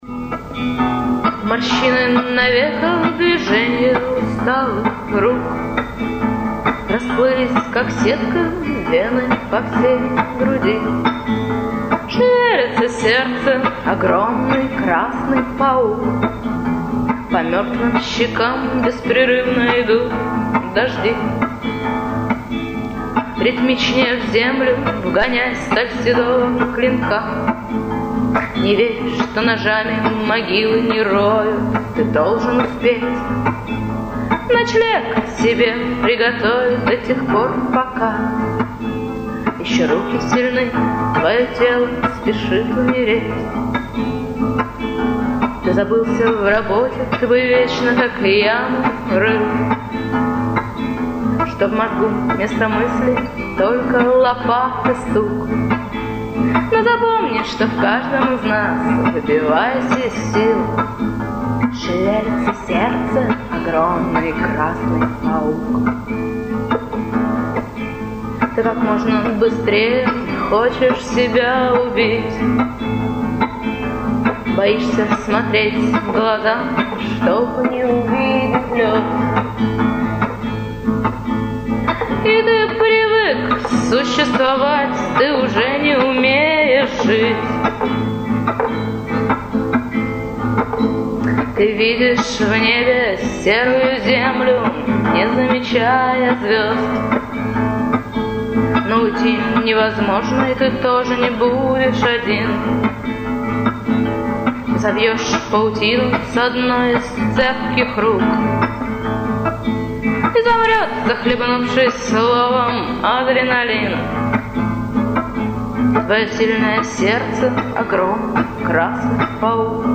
Live, Киев 1998